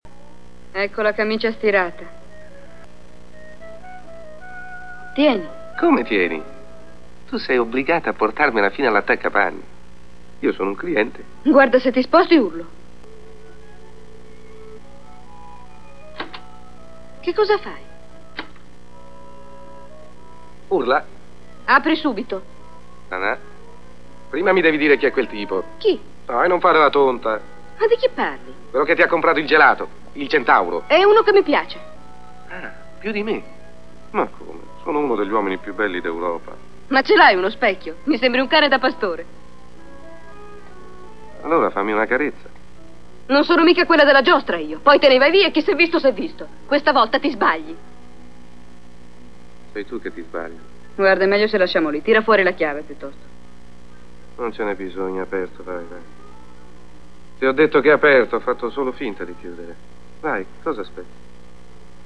voce di Adriana Asti nel film "Un ettaro di cielo", in cui doppia Rosanna Schiaffino.